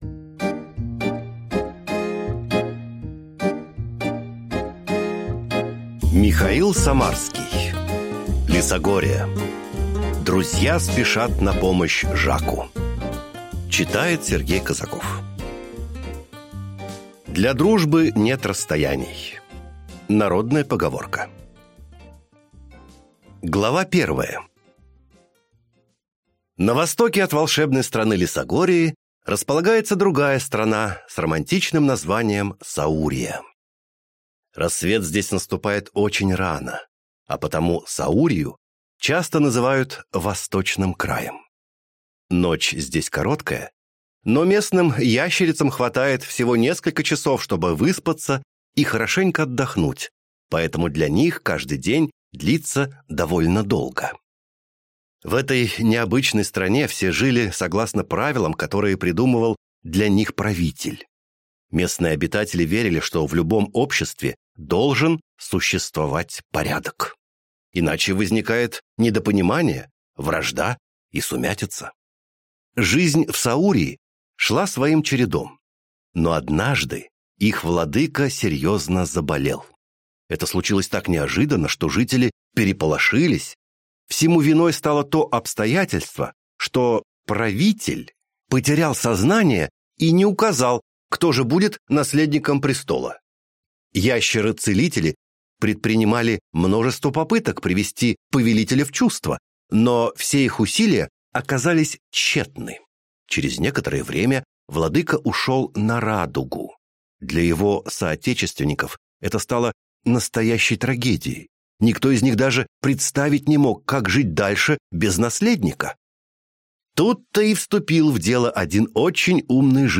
Аудиокнига Лесогория. Друзья спешат на помощь Жаку | Библиотека аудиокниг